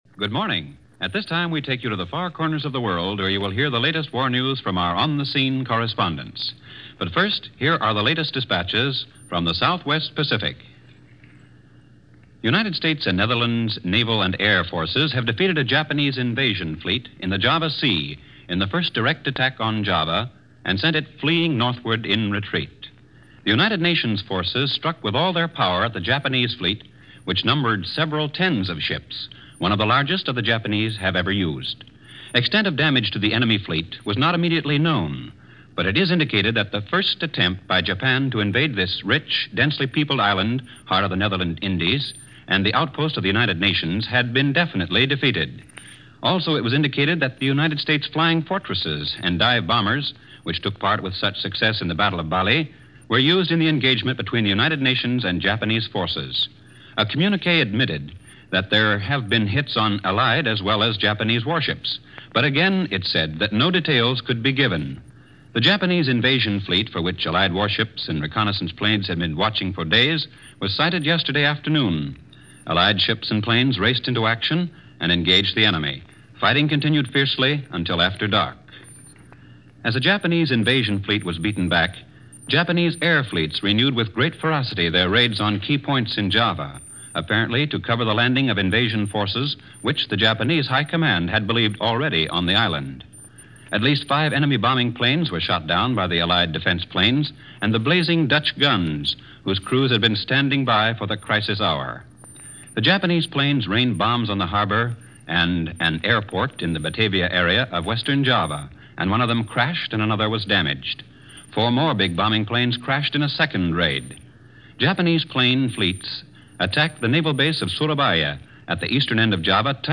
February 28, 1942 – While the news was coming in over the threatened Japanese invasion Java in the Pacific, a bulletin had flashed just towards the end of this newscast.